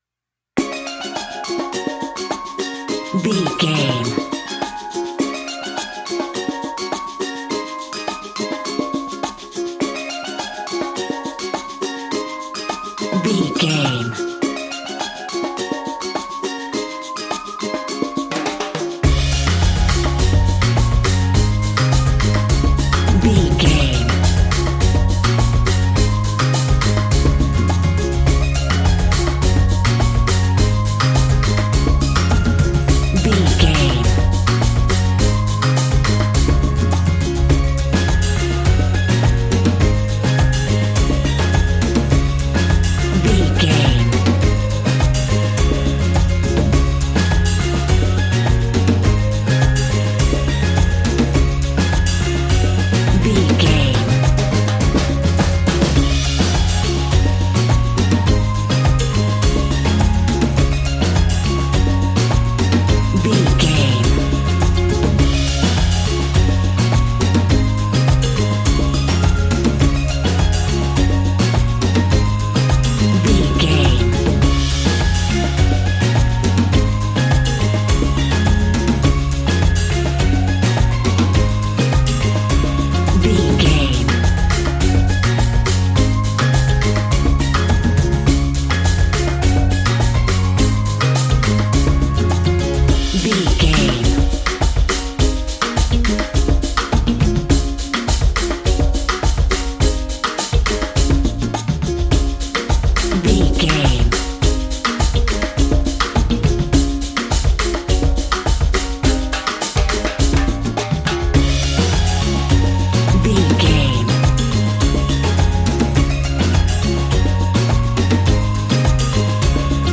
Ionian/Major
D♭
cheerful/happy
mellow
drums
electric guitar
percussion
horns
electric organ